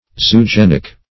Search Result for " zoogenic" : The Collaborative International Dictionary of English v.0.48: Zoogenic \Zo`o*gen"ic\, a. [Zoo- + -gen + -ic: cf. Gr.